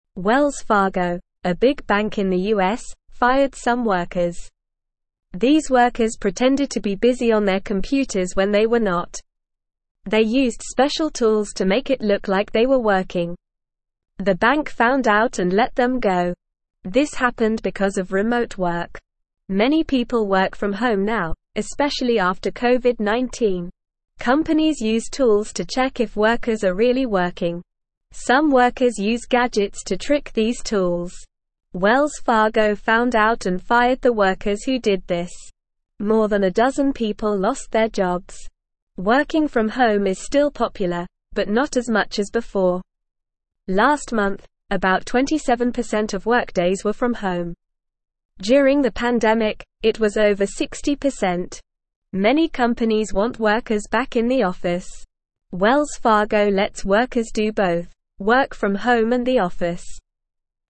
Normal
English-Newsroom-Beginner-NORMAL-Reading-Wells-Fargo-Fires-Workers-for-Pretending-to-Work.mp3